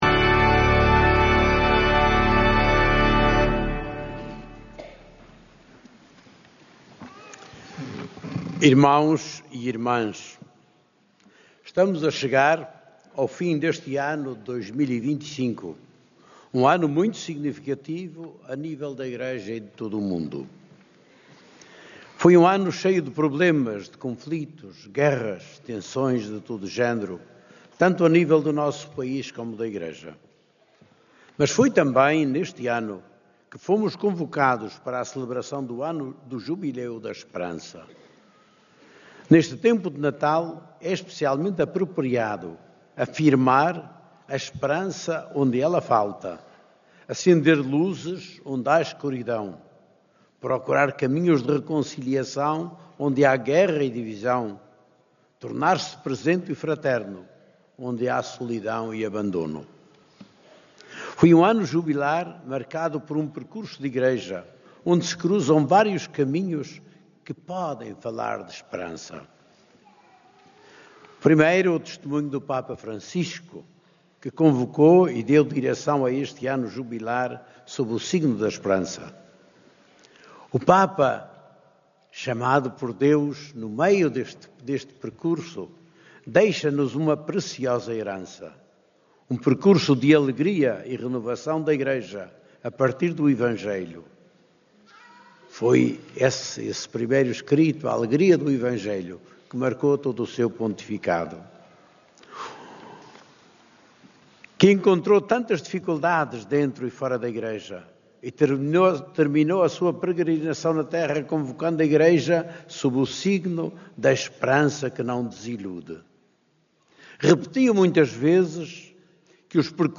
Este domingo, na missa da Festa da Sagrada Família, o bispo de Leiria-Fátima encerrou o ano jubilar com um apelo a fazer perdurar no tempo a esperança que orientou este Ano Santo da Igreja. Na homilia, D. José Ornelas referiu diversos âmbitos da vida da Igreja e da sociedade onde a esperança deve estar presente.